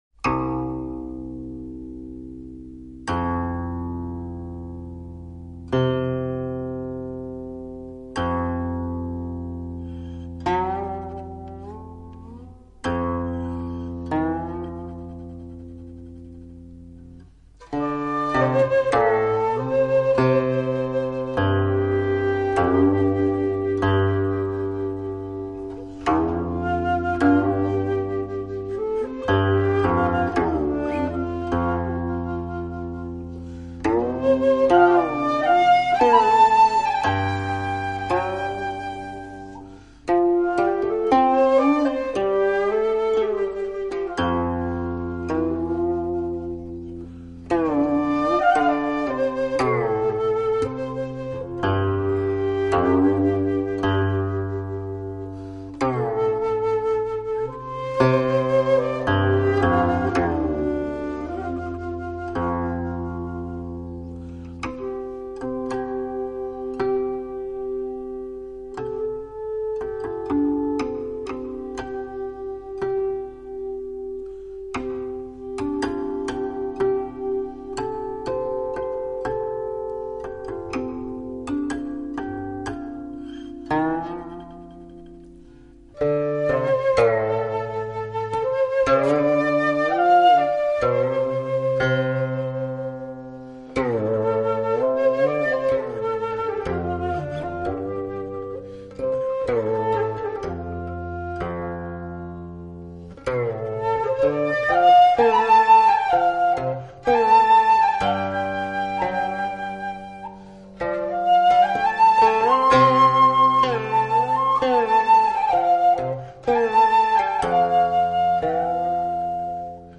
类别：古琴